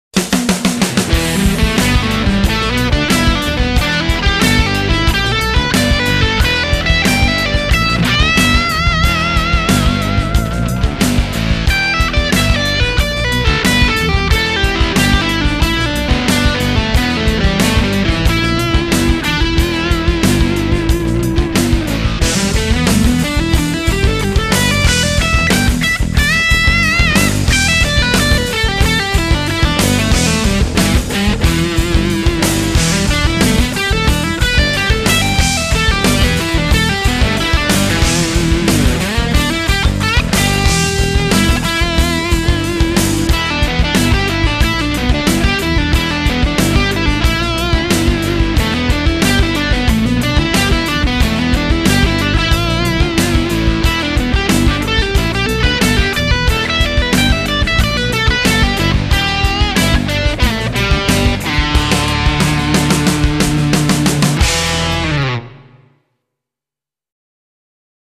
Pentatonic hammer-on and pull-off sequences Exercise